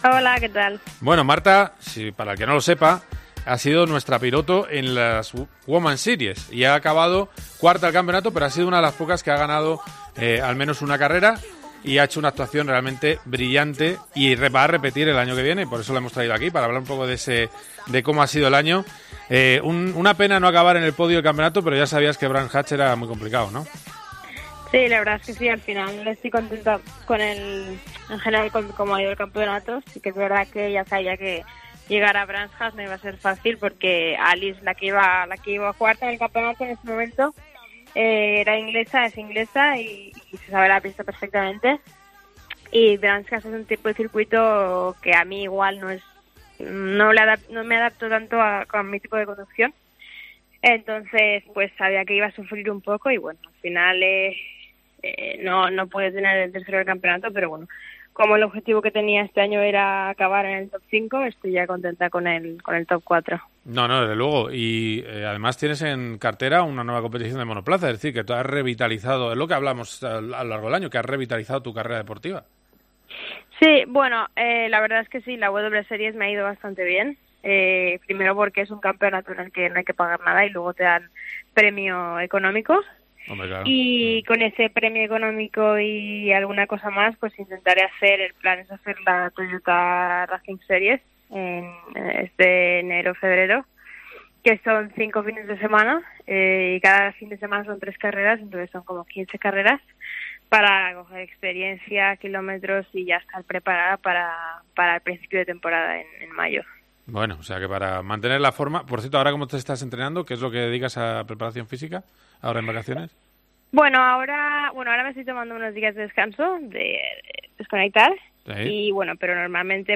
Marta García, piloto de W Series, pasa por los micrófonos de COPE GP tras terminar cuarta el campeonato.